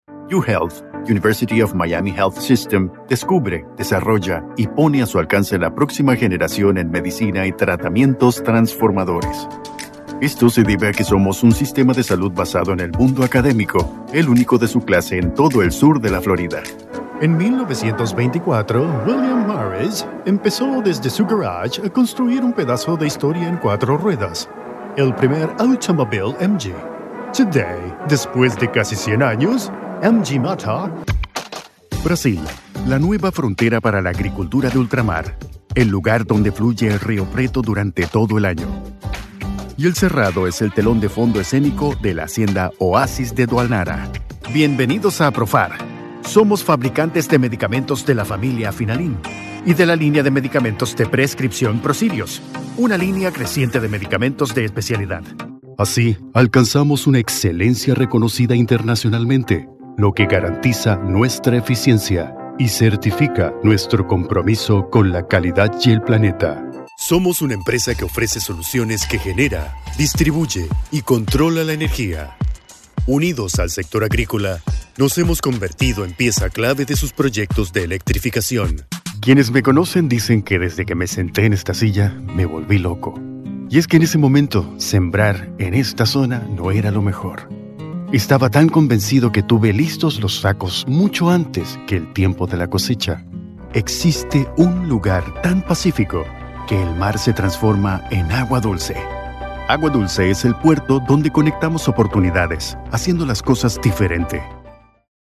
Spaans (Latijns Amerikaans)
Commercieel, Natuurlijk, Speels, Veelzijdig, Zakelijk
Corporate